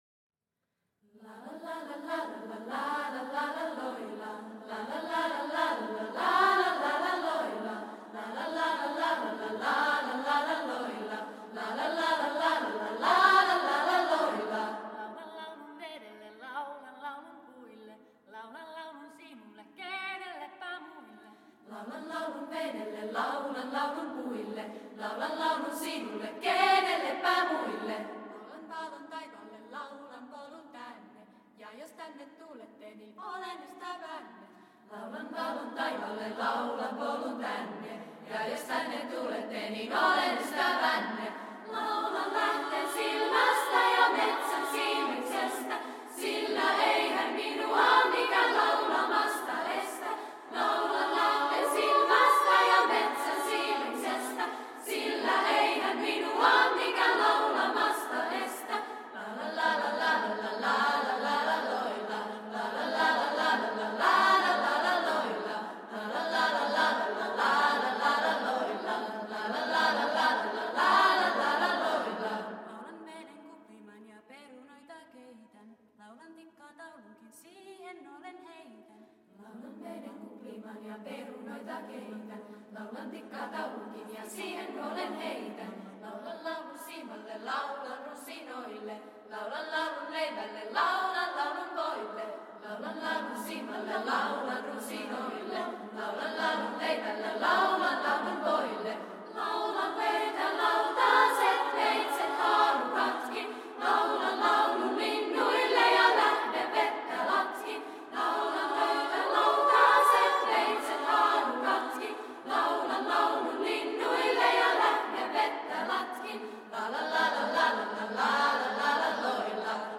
Una mostra de cançó popular cantada per la coral de l'institut finès